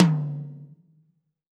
PTOM 6.wav